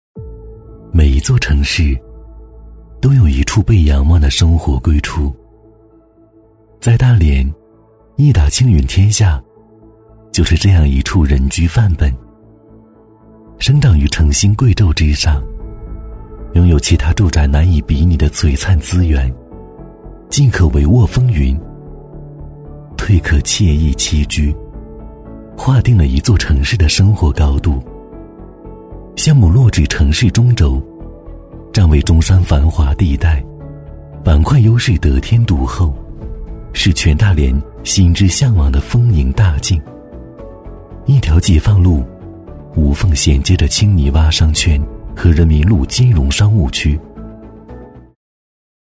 • 房地产广告配音
男90-地产宣传片《 亿达青云天下》-舒缓唯美
男90-地产宣传片《 亿达青云天下》-舒缓唯美.mp3